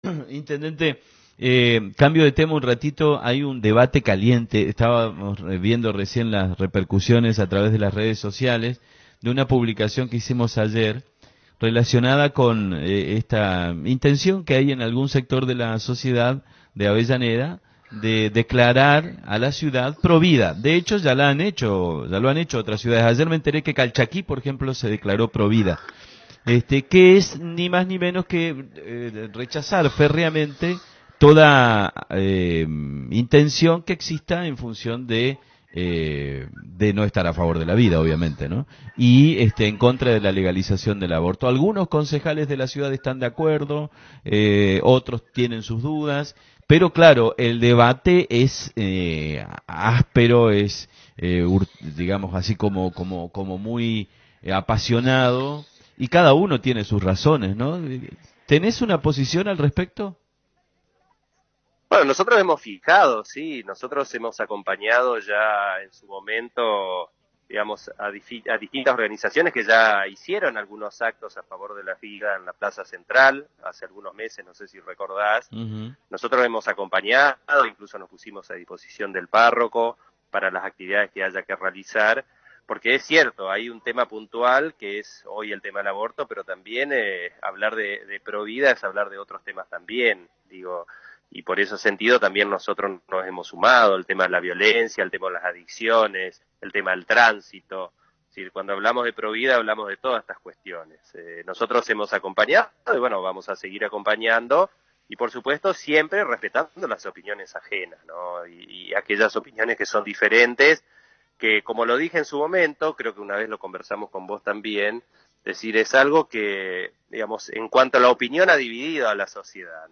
Desde España, el Intendente Scarpin habló sobre la posibilidad de de declarar Pro Vida a Avellaneda.